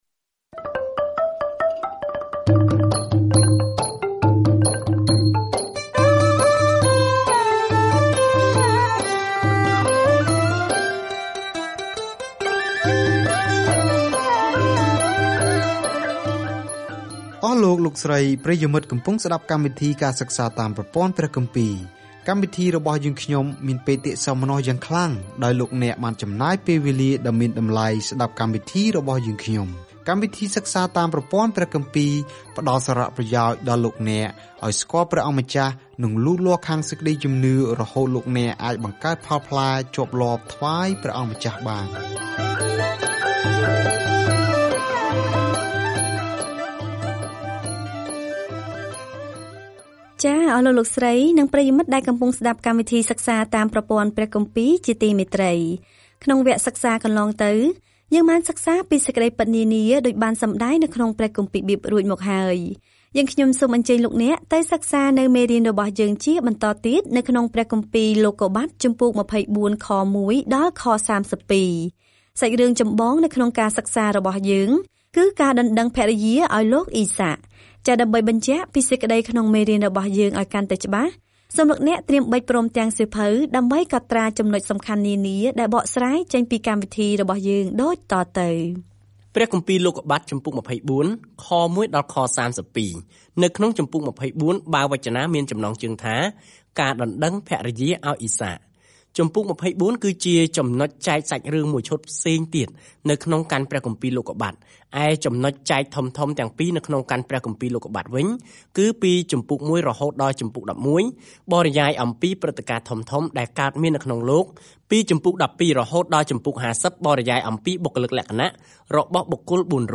ការធ្វើដំណើរប្រចាំថ្ងៃតាមរយៈលោកុប្បត្តិ នៅពេលអ្នកស្តាប់ការសិក្សាជាសំឡេង ហើយអានខគម្ពីរដែលបានជ្រើសរើសពីព្រះបន្ទូលរបស់ព្រះនៅក្នុងសៀវភៅលោកុប្បត្តិ។